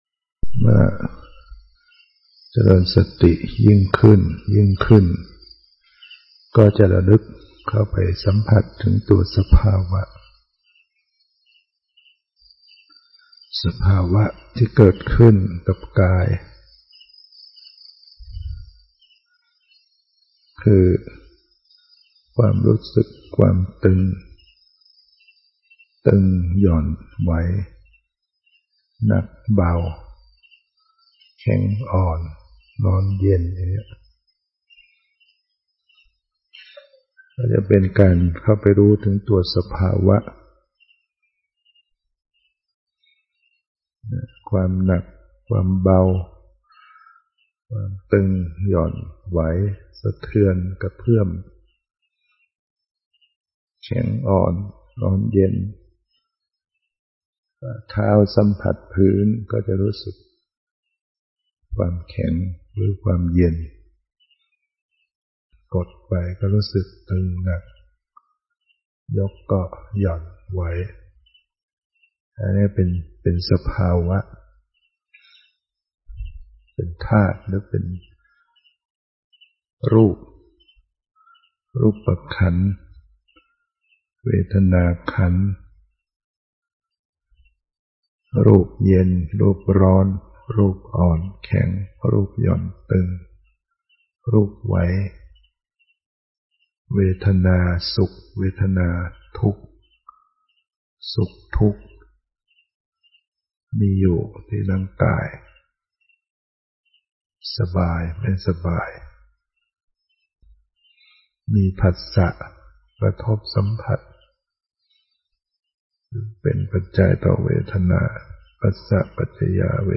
นำปฏฺิบัติ เดิน